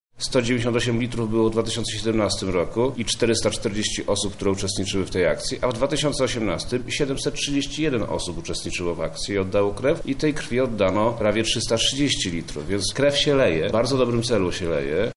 Jak mówi wojewoda lubelski, Przemysław Czarnek, akcja sukcesywnie się rozwija: